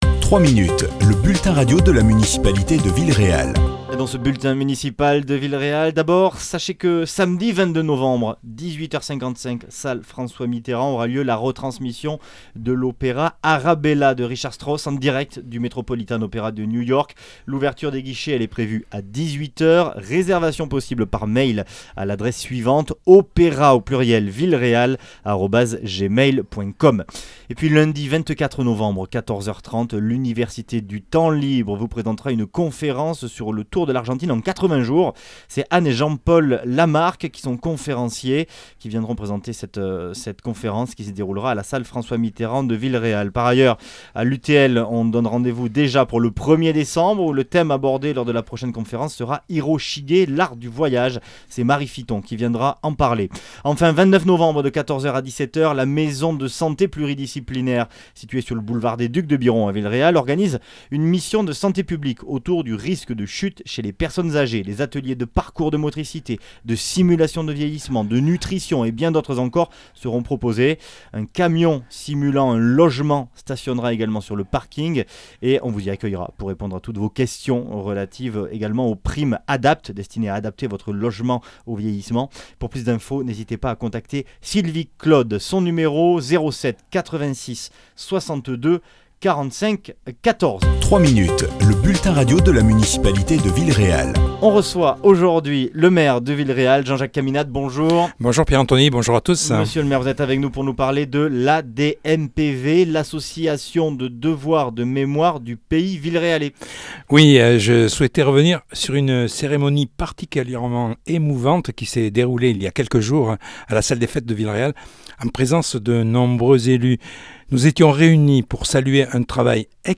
Les explications du maire Jean-Jacques Caminade.